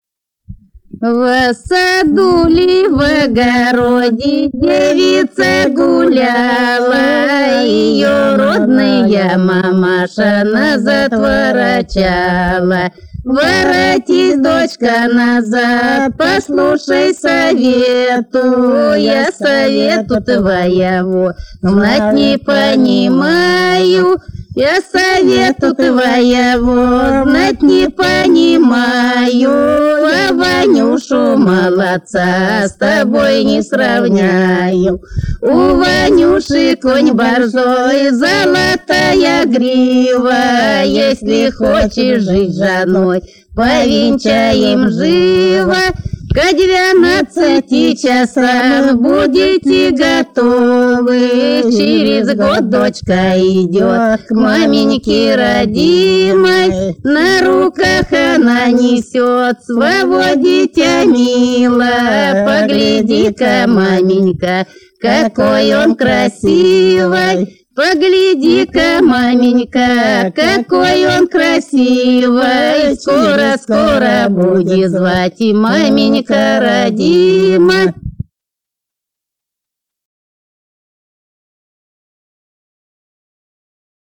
Народные песни Касимовского района Рязанской области «Во саду ли, в огороди», плясовая.